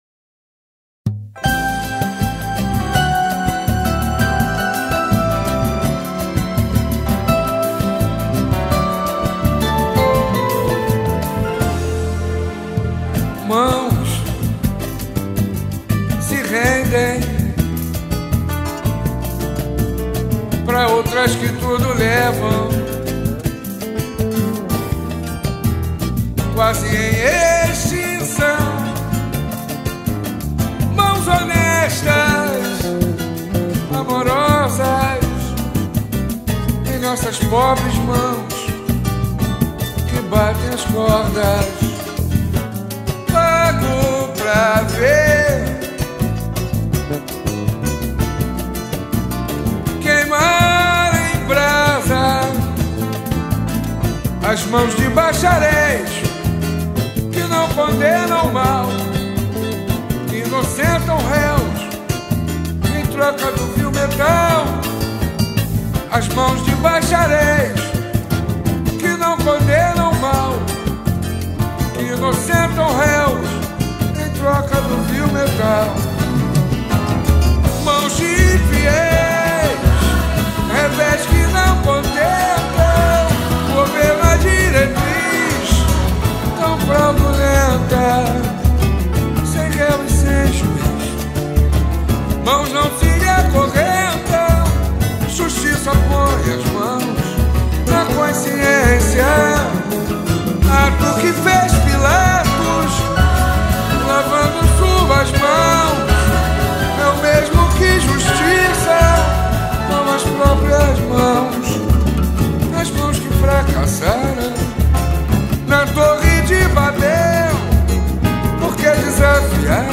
Samba E Pagode